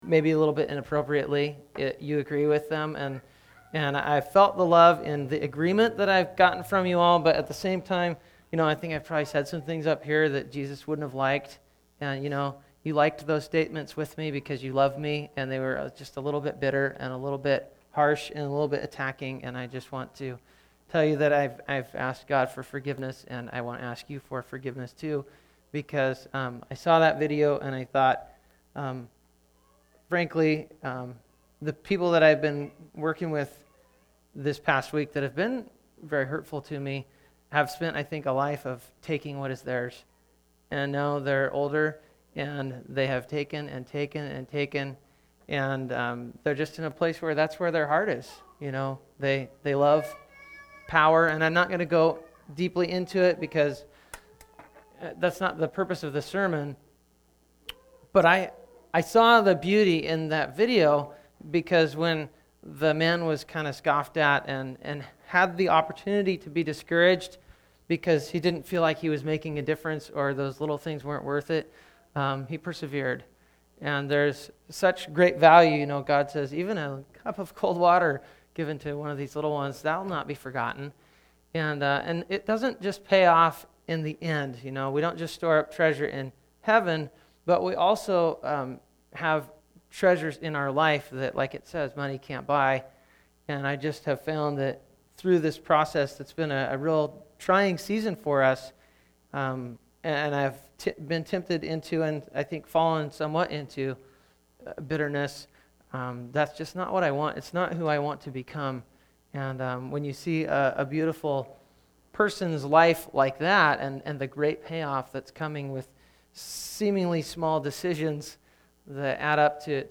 4-13-14 Bitterness sermon.mp3